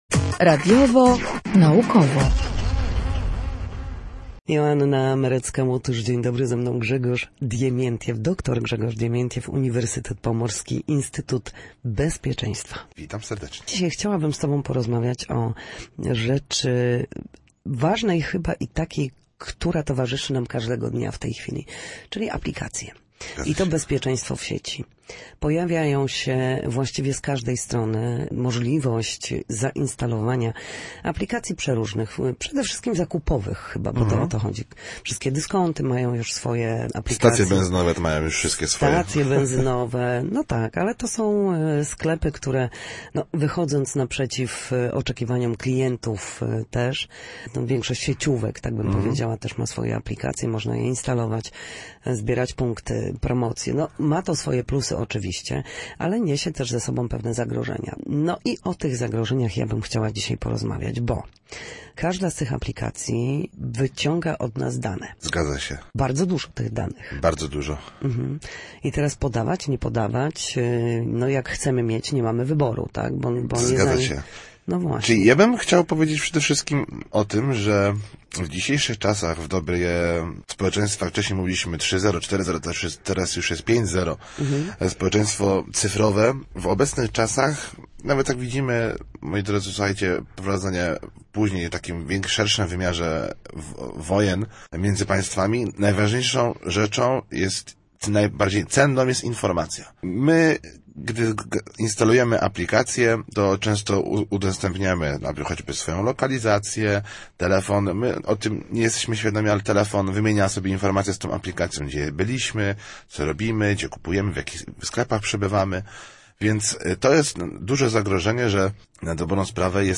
Socjolog opowiadał o bezpiecznym instalowaniu aplikacji.